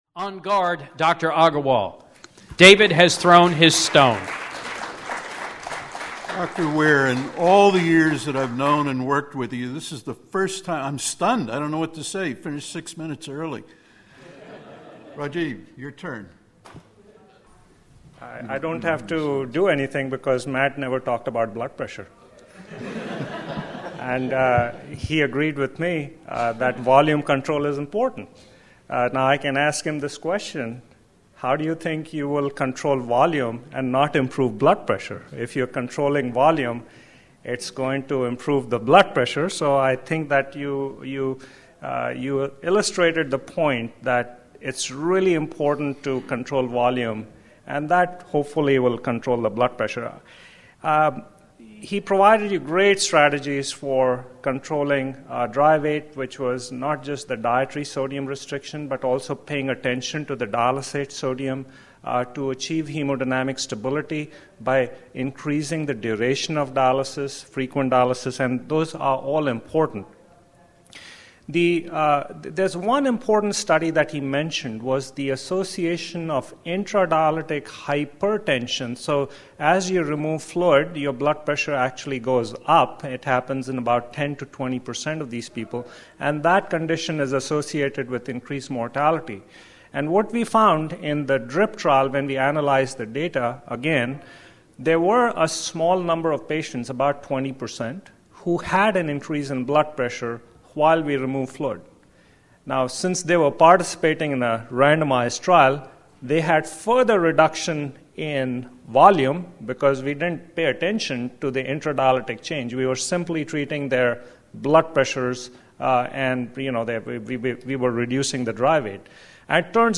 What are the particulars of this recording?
American Society of Hypertension 2012